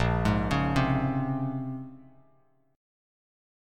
A#m9 chord